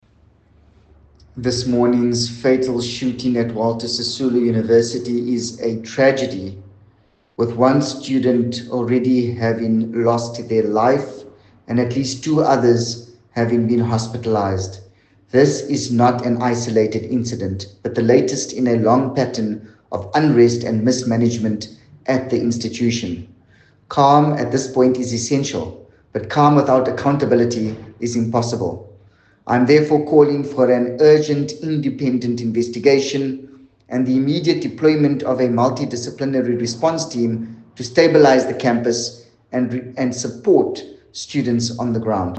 English from Yusuf Cassim MPL